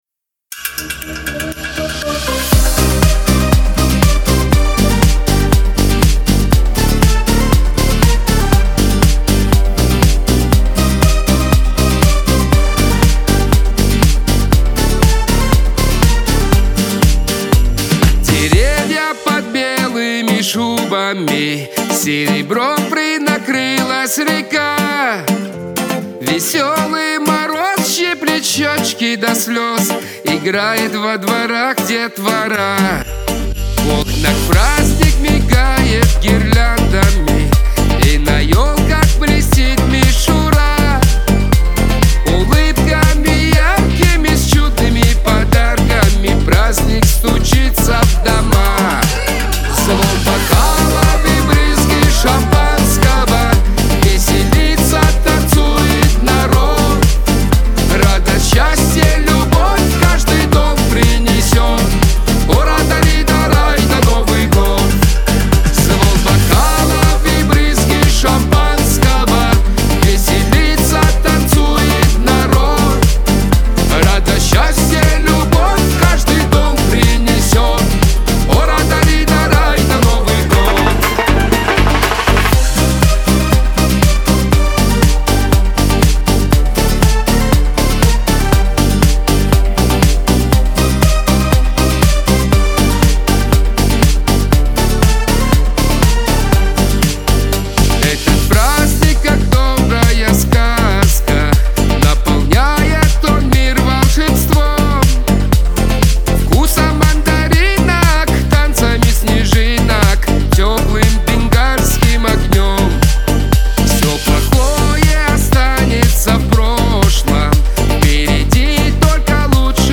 dance
эстрада